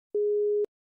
10. Телефонный гудок в наушниках AirPods
airpods-zvonok-2.mp3